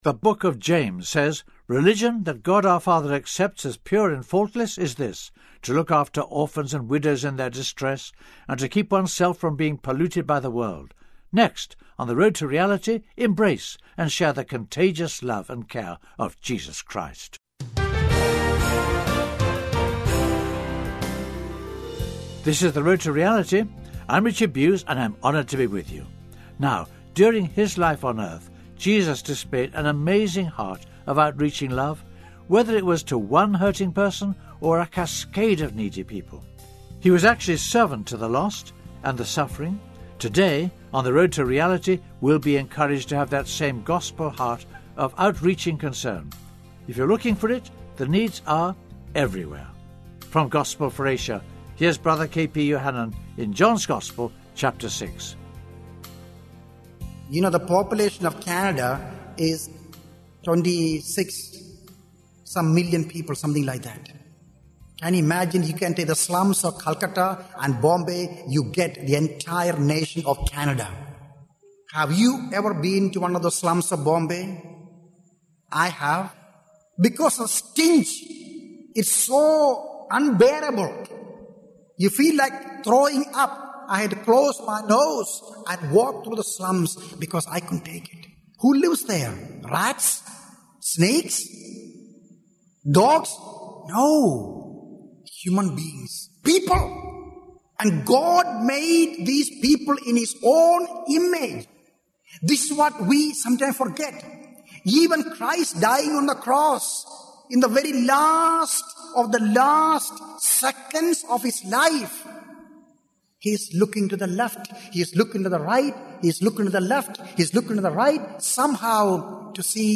In this sermon, the speaker emphasizes the importance of focusing on others rather than ourselves.